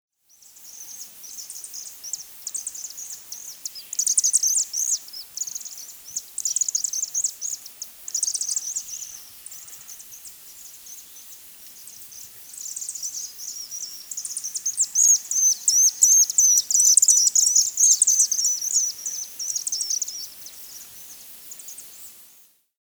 На этой странице собраны звуки, издаваемые стрижами: их звонкое щебетание, крики в полёте и другие природные голоса.
Голос серобрюхой иглохвостки